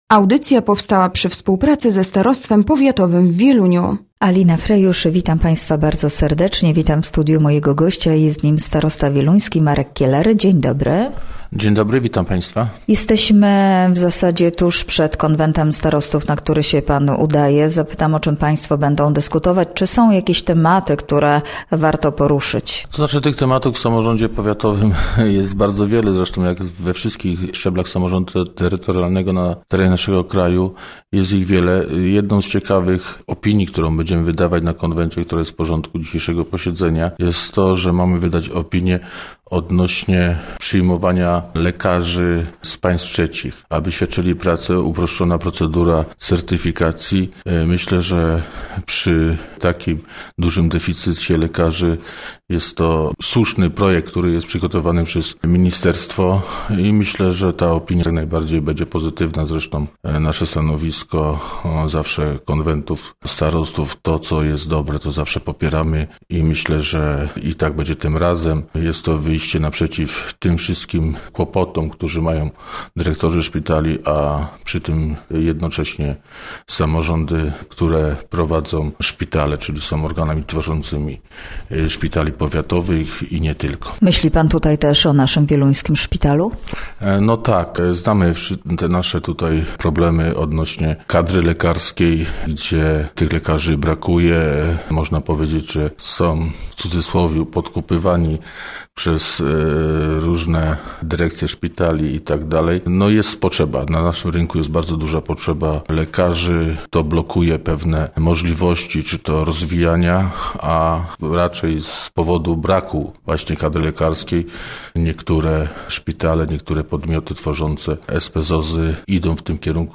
Gościem Radia ZW był starosta wieluński, Marek Kieler